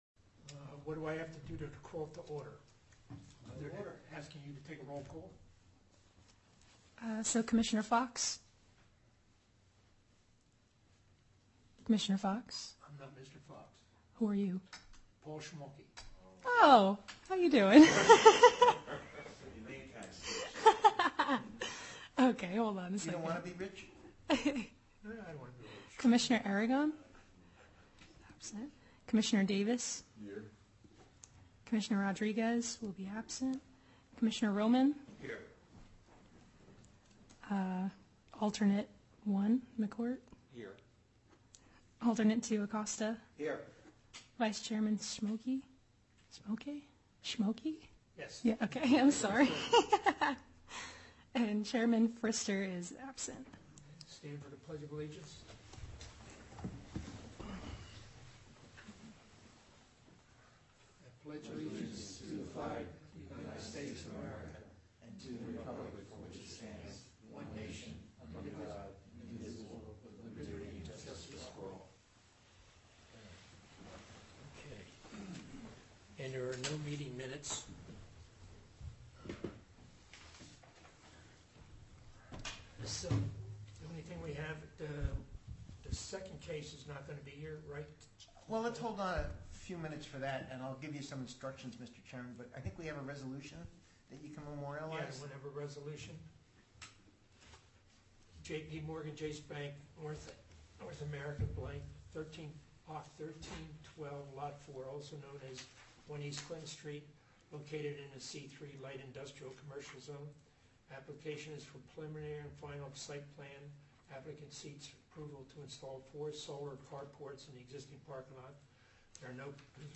Meeting Information Back To Meeting Schedule Meeting Type : Board of Adjustment Date/Time : Thursday December 5, 2024 @ 7:00 PM Place : Town Hall - 37 No Sussex St. Dover File Listing View Agenda MEETING RECORDING